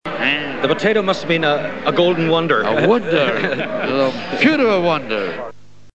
Dinner at the Hurling Club of Buenos Aires, 25 May 1987,